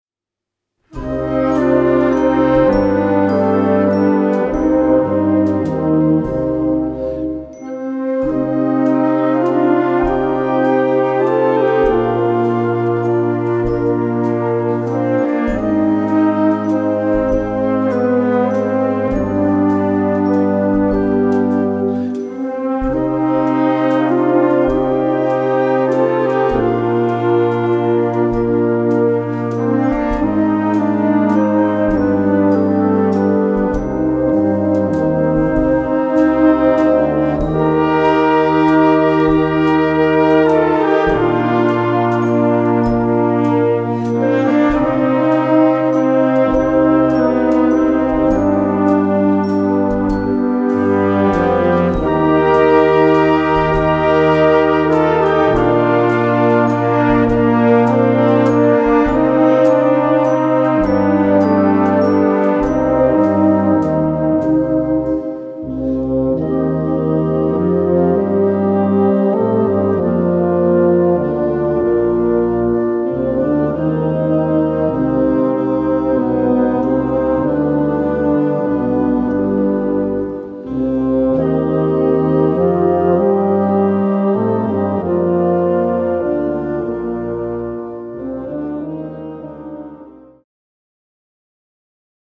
Traditional Old English Tune, 16th Century.
A4 Besetzung: Blasorchester Zu hören auf